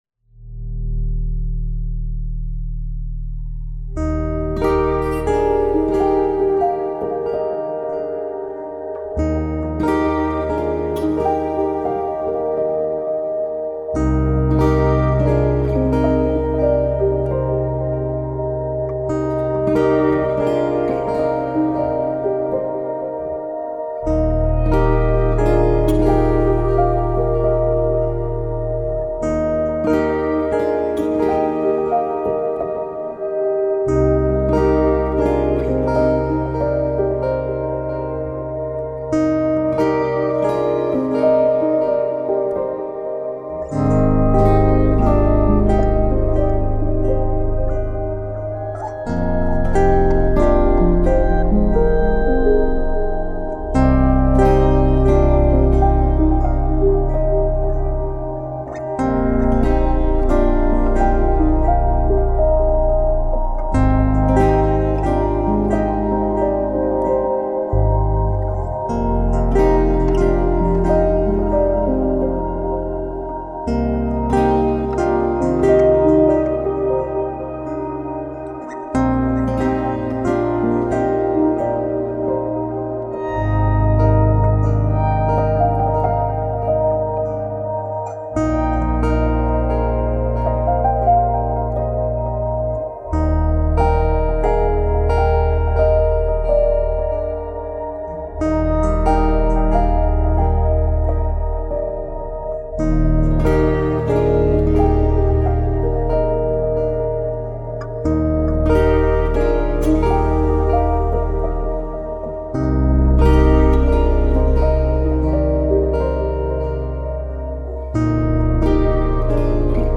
موسیقی بی کلام آرامش بخش پیانو عصر جدید گیتار
موسیقی بی کلام پیانو موسیقی بی کلام گیتار